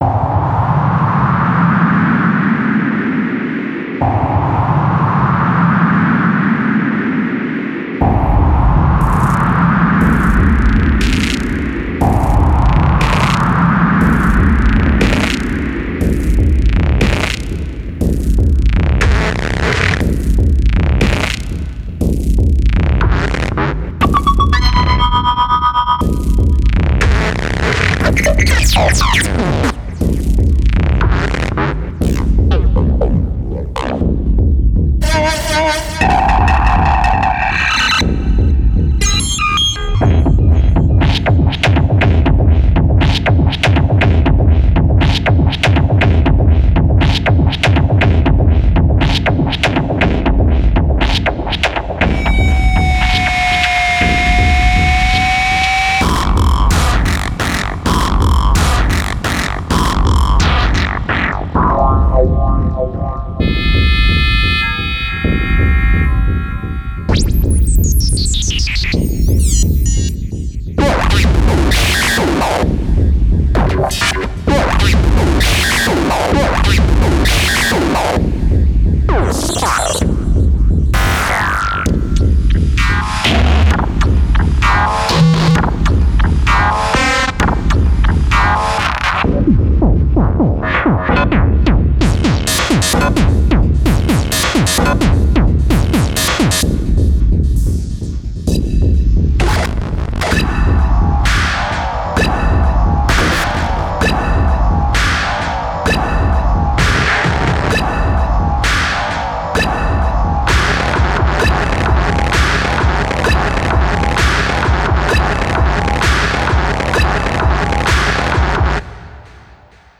60 Sample loops - 120 / 130 BPM